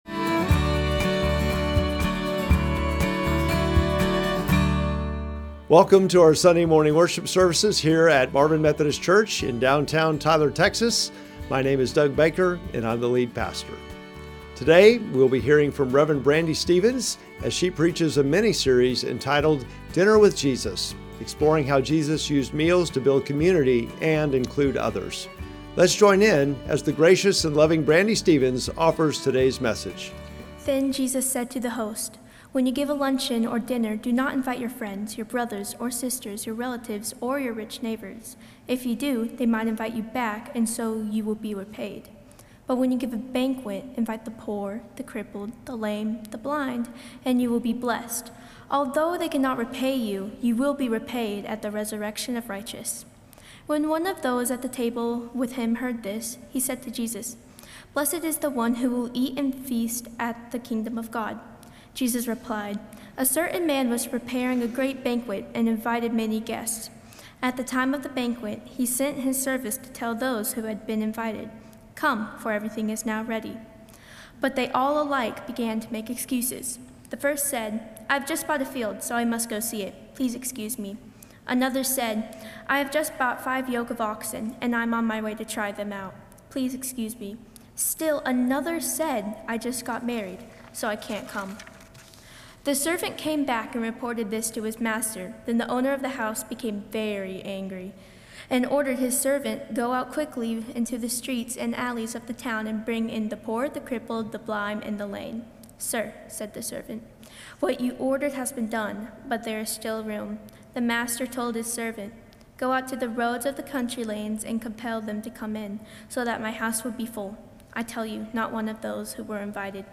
Sermon text: Luke 14:12-24